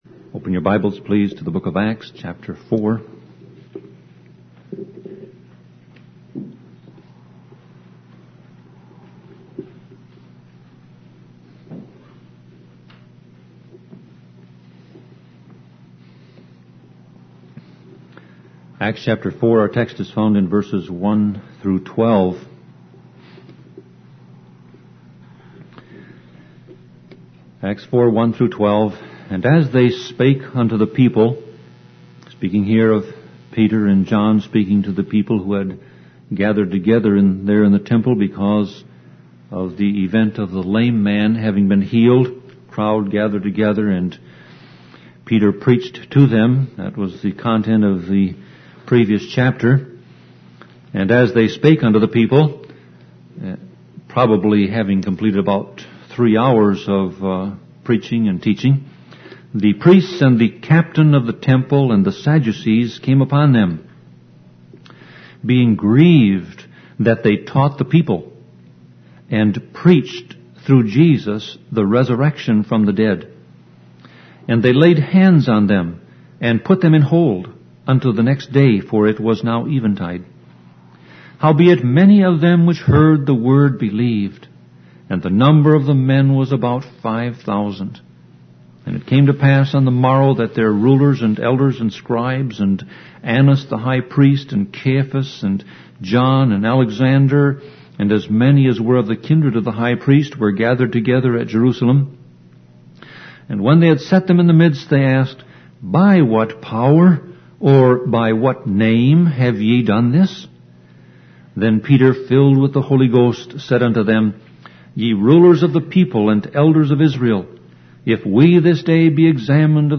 Sermon Audio Passage: Acts 4:1-12 Service Type